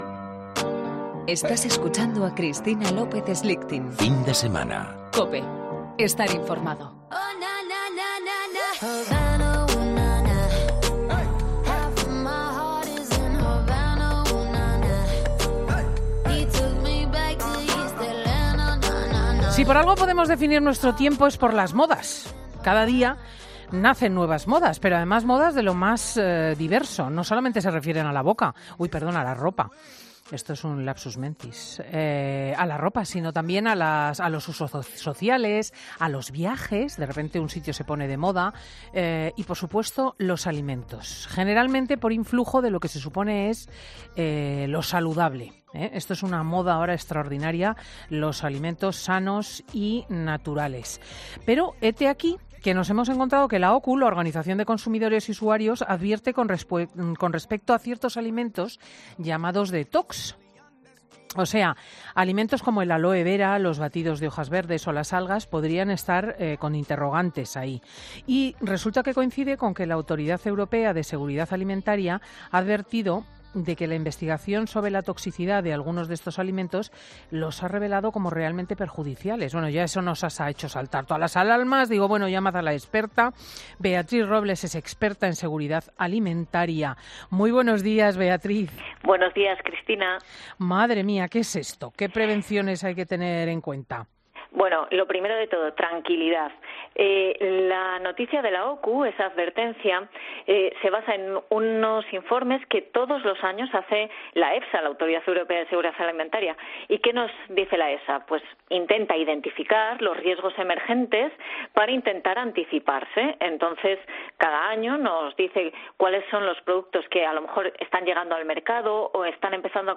Presentado por Cristina López Schlichting, prestigiosa comunicadora de radio y articulista en prensa, es un magazine que se emite en COPE, los sábados y domingos, de 10.00 a 14.00 horas, y que siguen 769.000 oyentes, según el último Estudio General de Medios conocido en noviembre de 2017 y que registró un fuerte incremento del 52% en la audiencia de este programa.